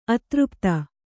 ♪ atřpta